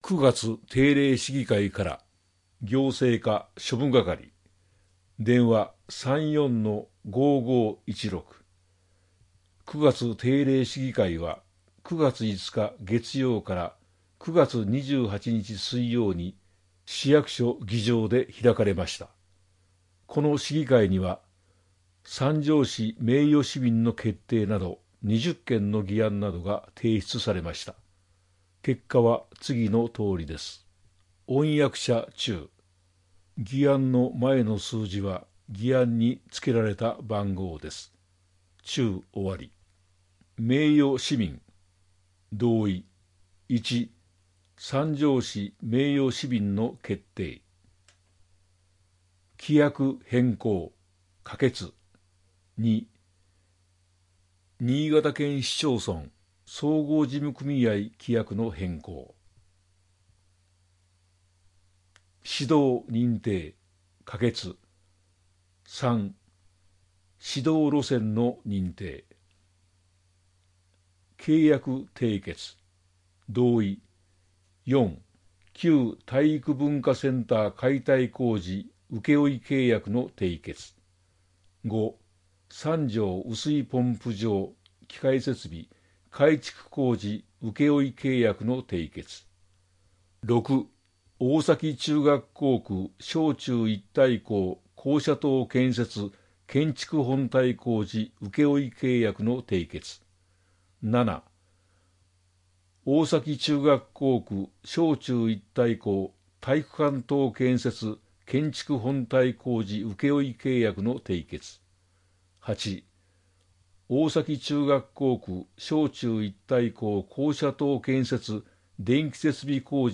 広報さんじょうを音声でお届けします。